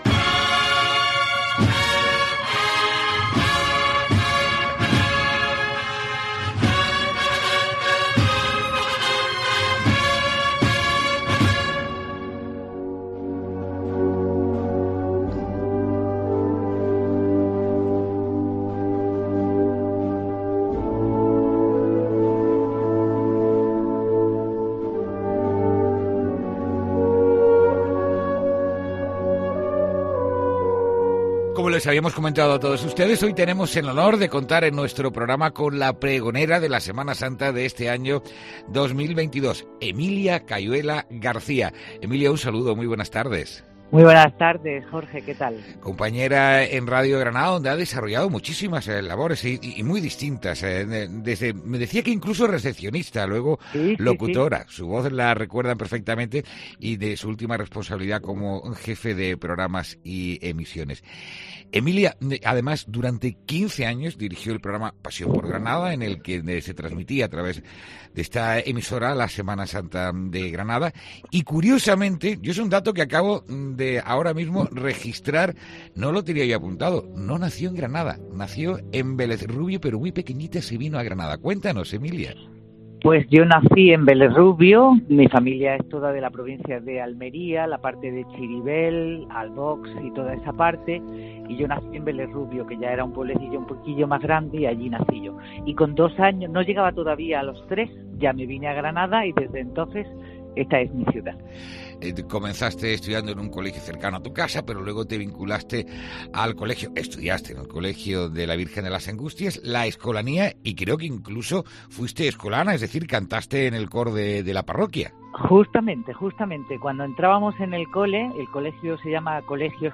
El AUDIO de su intervención acompaña esta información.